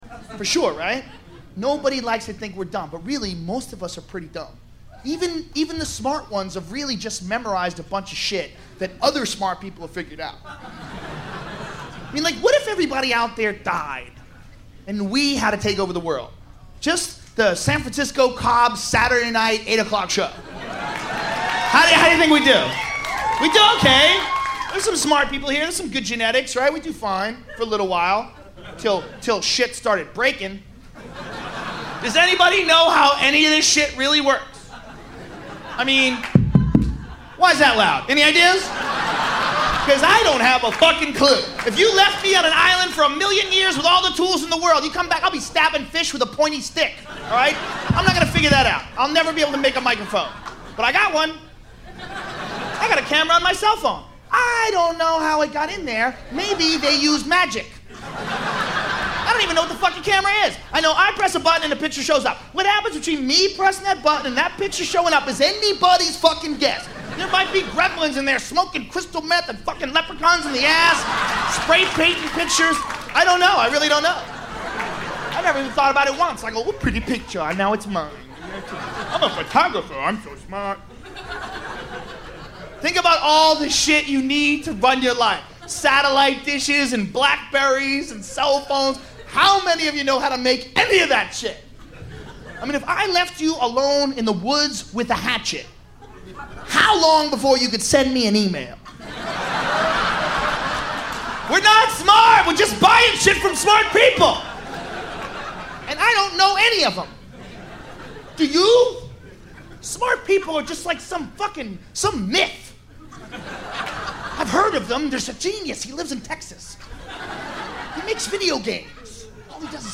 The comedian's name is Joe Rogan and the cut is from his comedy album entitled "Shiny Happy Jihad".